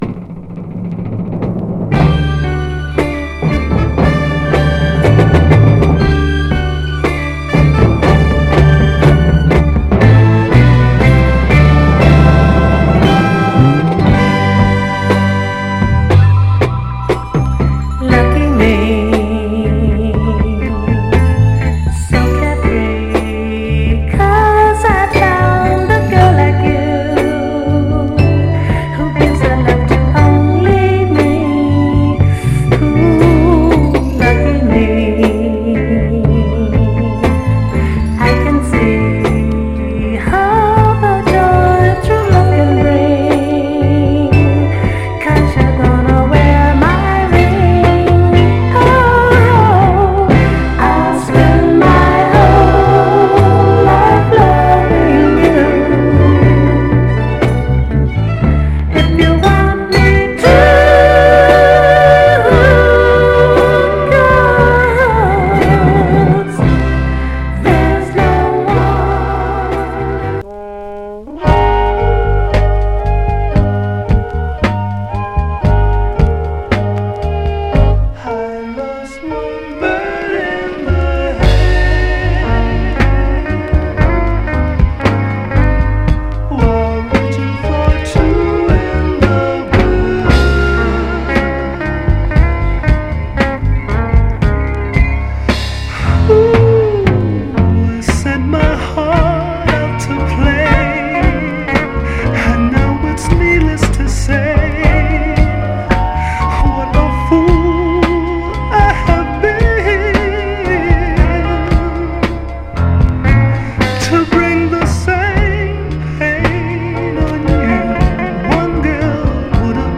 序盤で少しチリつきますが、目立つノイズは少なく全体的にはプレイ概ね良好です。
※試聴音源は実際にお送りする商品から録音したものです※